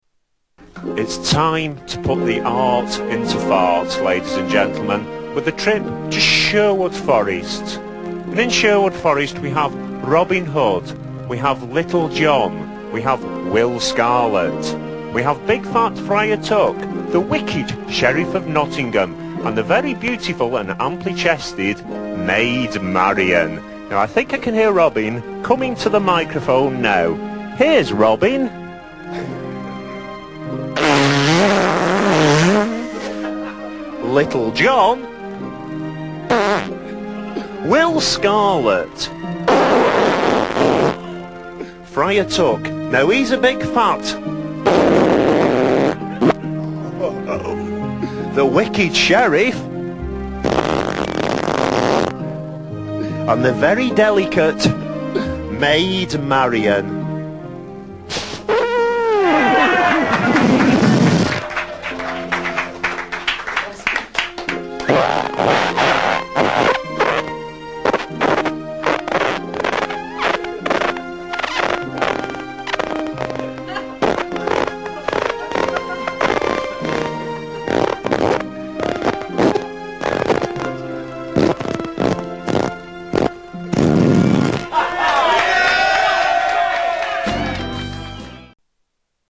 Tags: Comedians Mr Methane Fart Fart Music Paul Oldfield